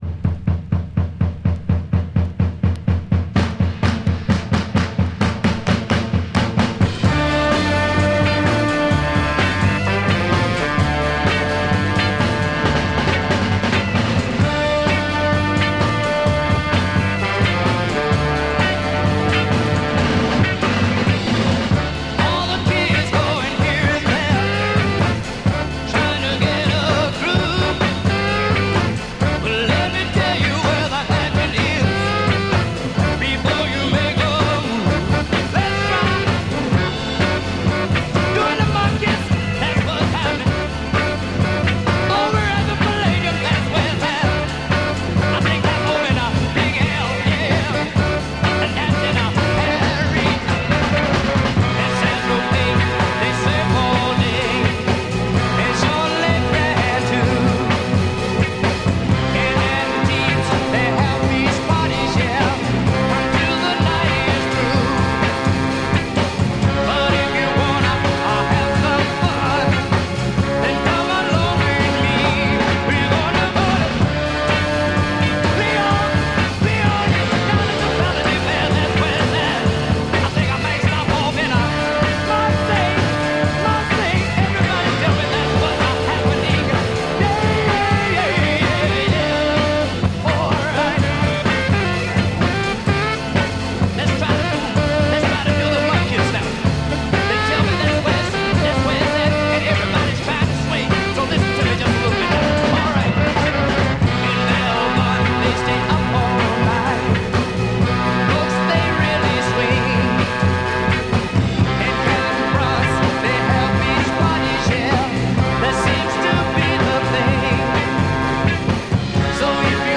Killer Soul Break Mod